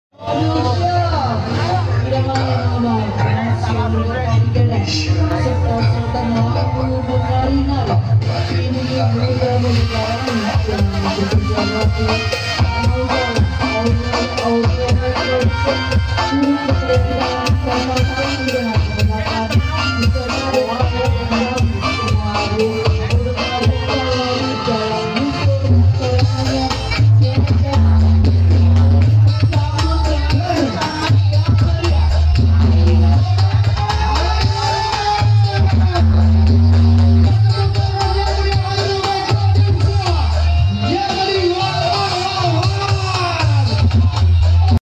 AEROMAX TAMPIL DI ACARA KARNAVAL